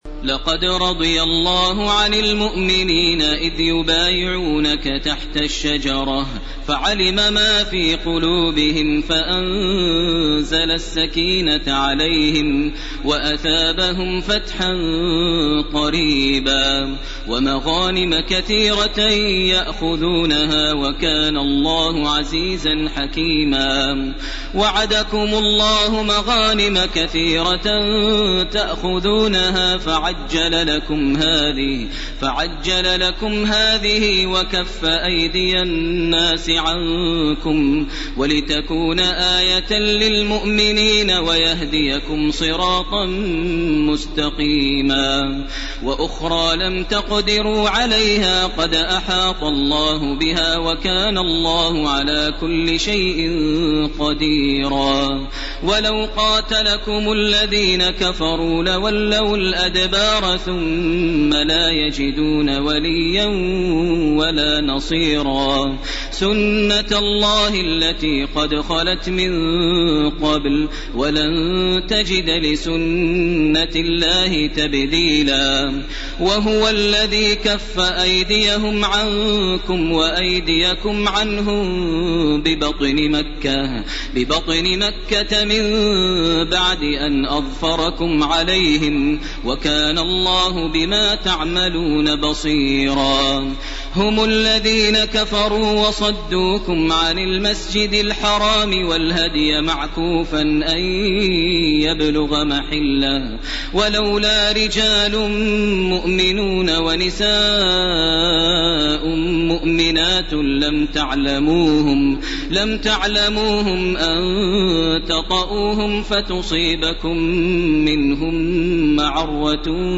Sura AlFath 18 to the end, Sura AlHujurat , Sura Qaf and Sura AdhDhariyat > Taraweh 1429 > Taraweeh - Maher Almuaiqly Recitations